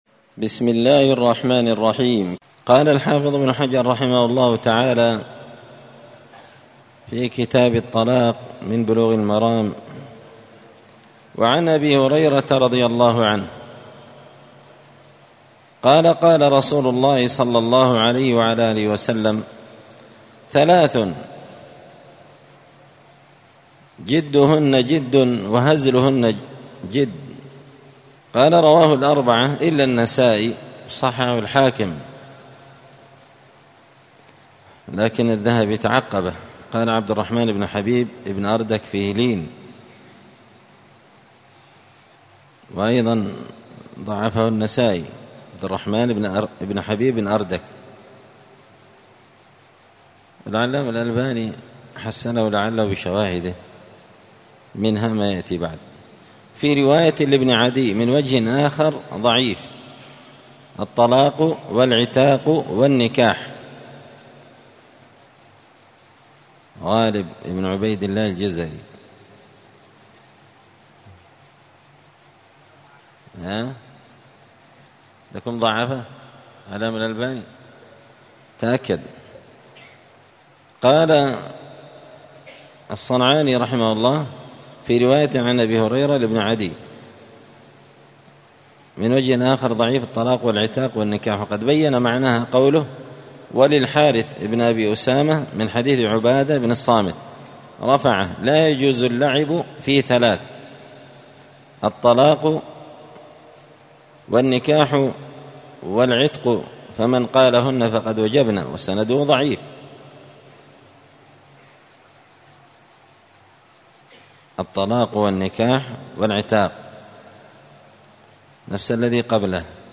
*الدرس الخامس (5) {حكم طلاق المازح والجاد والسكران والغضبان}*